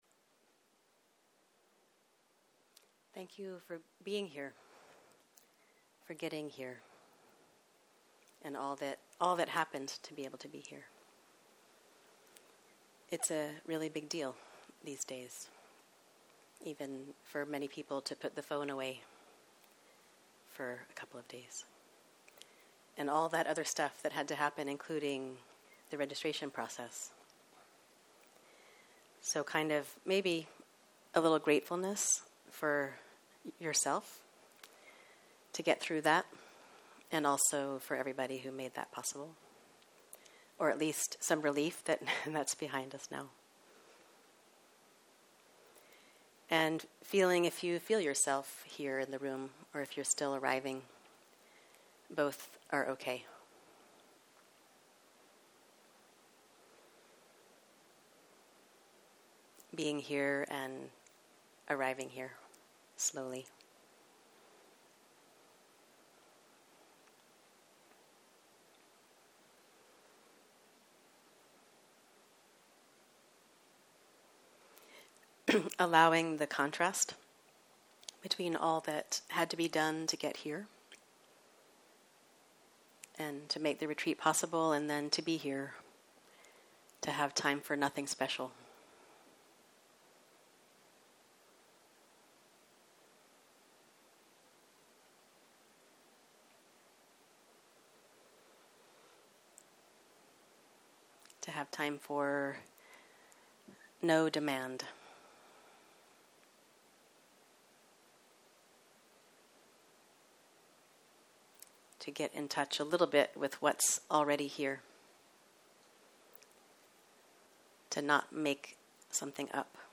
סוג ההקלטה: שיחת פתיחה שפת ההקלטה